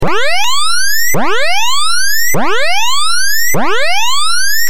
دانلود آهنگ هشدار 22 از افکت صوتی اشیاء
دانلود صدای هشدار 22 از ساعد نیوز با لینک مستقیم و کیفیت بالا
جلوه های صوتی